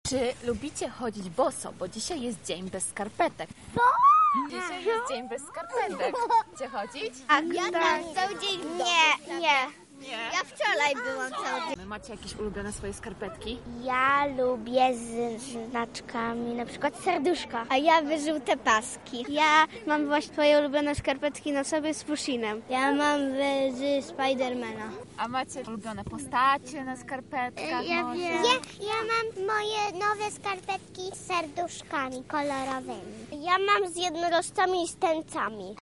Zapytaliśmy młodszych mieszkańców Lublina, czy obchodzą dzisiejsze święto oraz jaka para skarpetek należy do ich ulubionych:
SONDA